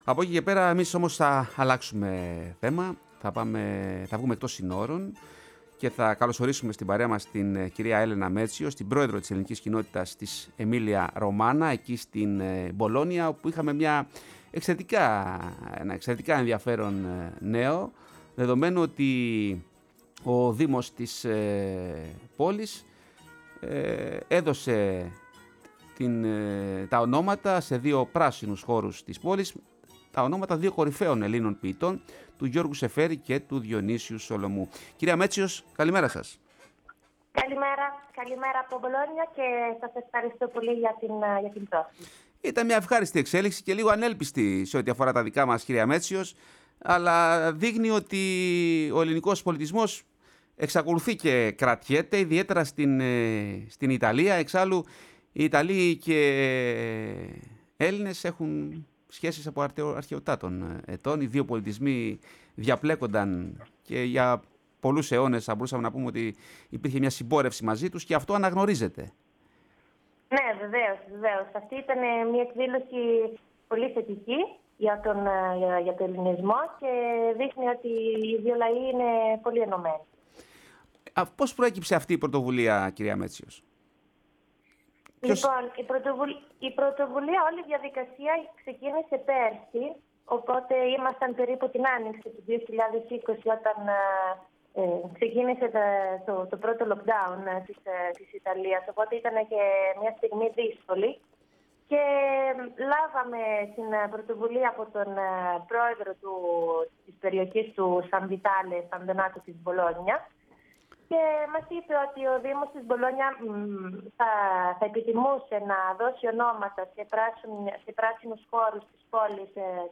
φιλοξενήθηκε στη Φωνή της Ελλάδας και στην εκπομπή “Η Ελλάδα στον κόσμο”